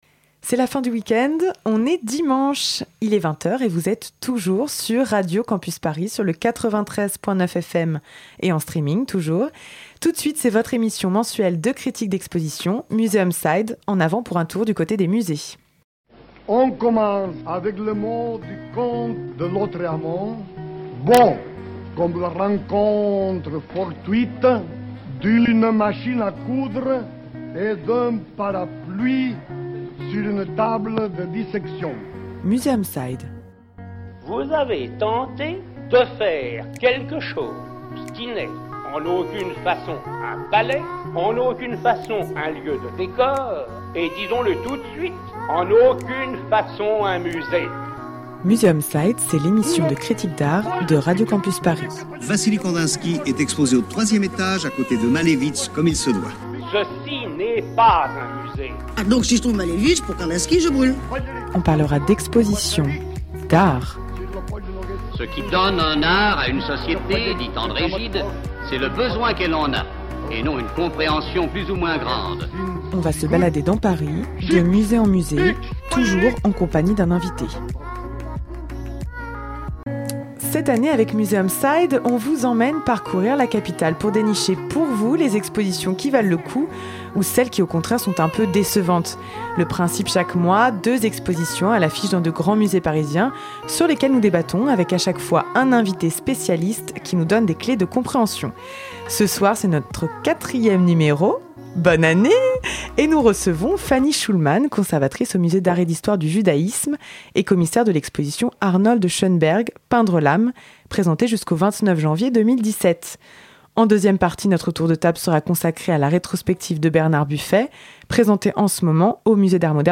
Cette saison, avec Museum side, on vous emmène parcourir la capitale pour dénicher pour vous les expositions qui valent le coup d’œil ou celles qui au contraire sont un peu décevantes. Le principe chaque mois : deux expositions à l'affiche dans de grands musées parisiens sur lesquelles nous débattons avec à chaque fois un invité spécialiste qui nous donne des clés de compréhension.